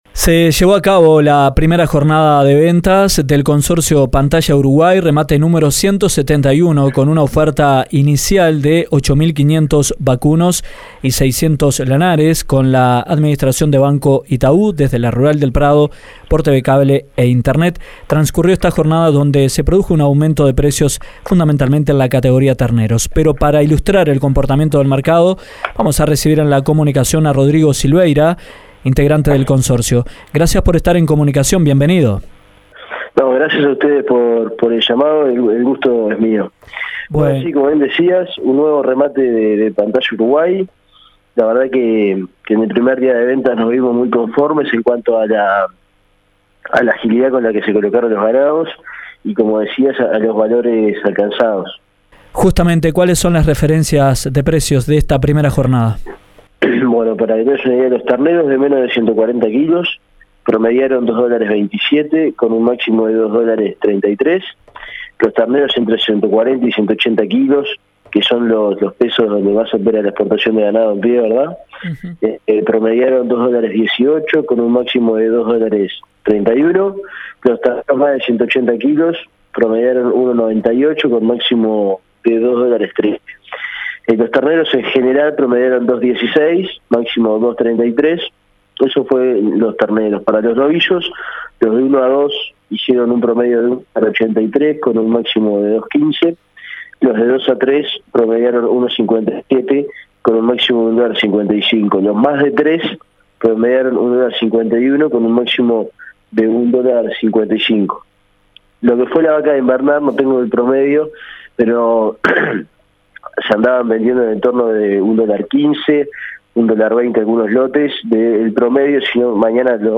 En entrevista con Dinámica Rural